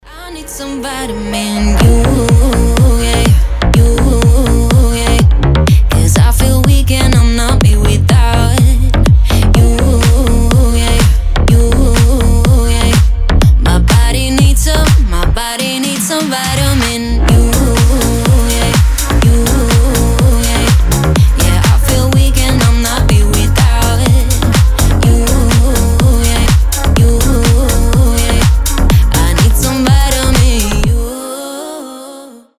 Dance Pop
house